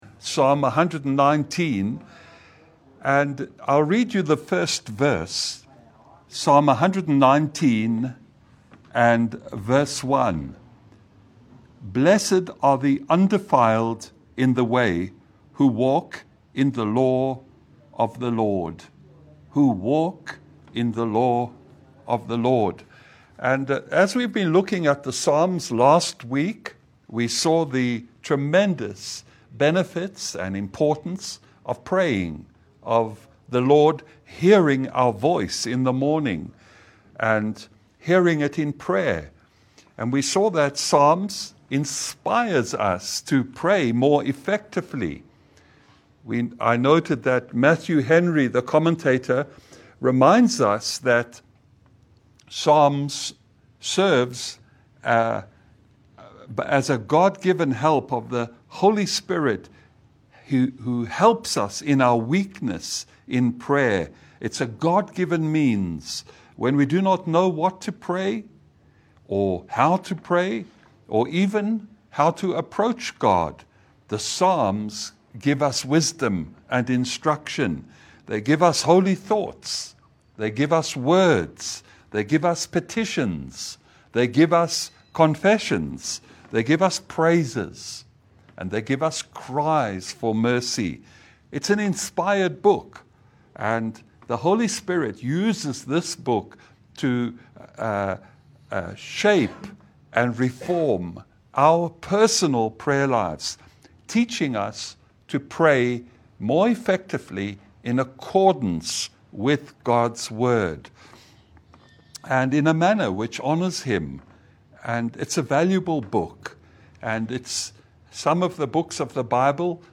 Service Type: Lunch hour Bible Study